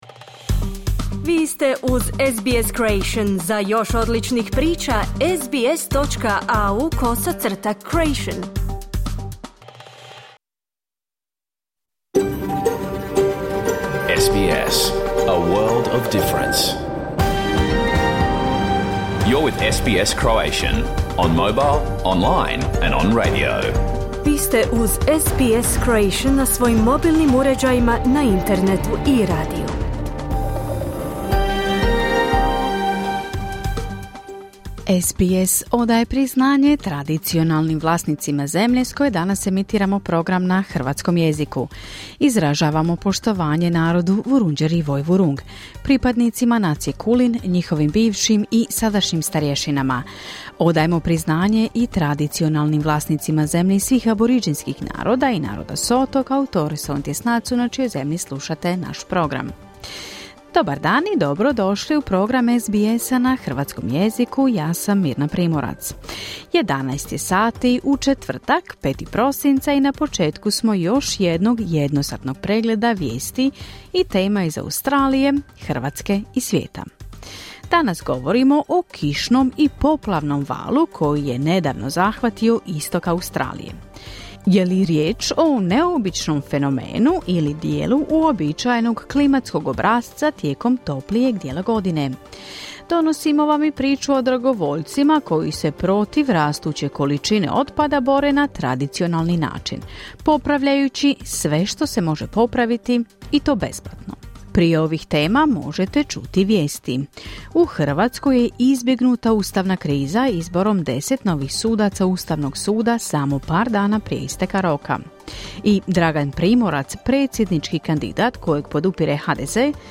Vijesti i aktualne teme iz Australije, Hrvatske i svijeta. Emitirano uživo na radiju SBS1 u četvrtak, 5. prosinca u 11 sati po istočnoaustralskom vremenu.